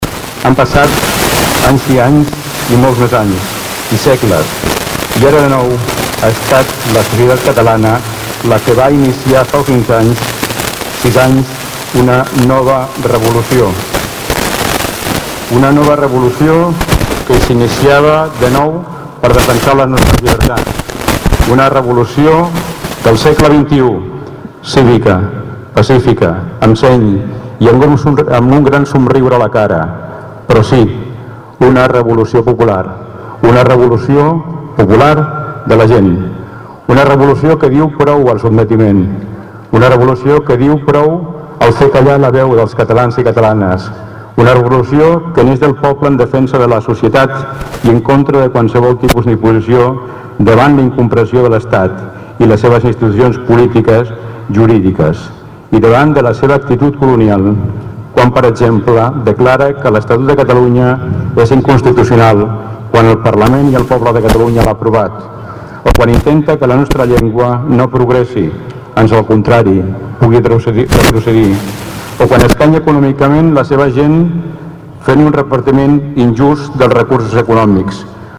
En el discurs institucional, Joan Carles Garcia va recordar com Catalunya va perdre les seves llibertats el 1714, amb la Guerra de Successió, però també va posar de manifest el moviment popular que va començar fa 6 anys per recuperar “l’estatus” perdut.
parlament-diada-1.mp3